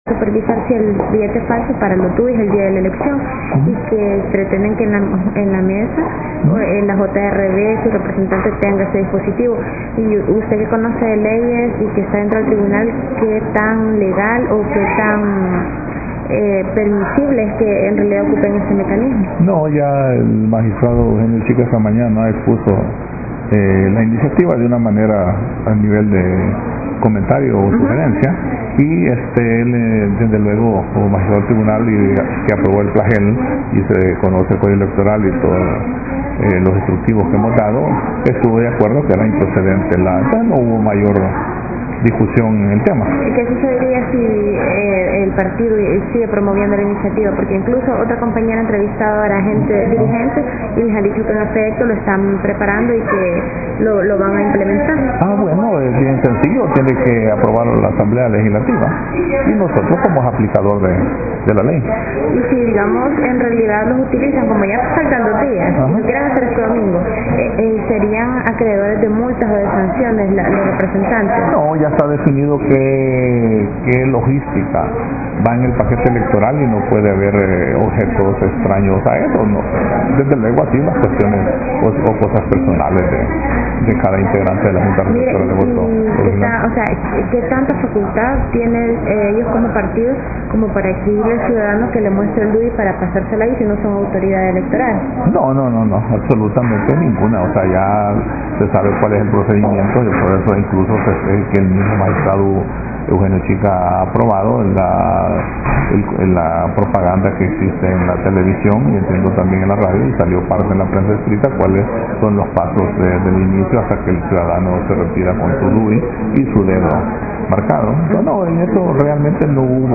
Declaraciones de Mario Salamanca magistardo del TSE